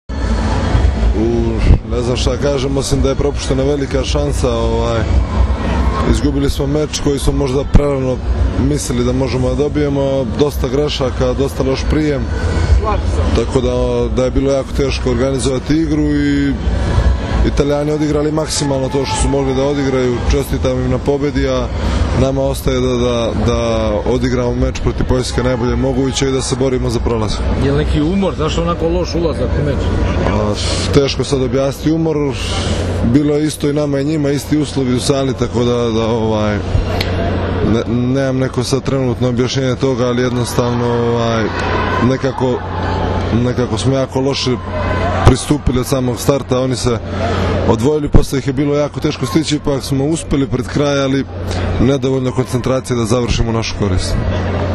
IZJAVA NEMANJE PETRIĆA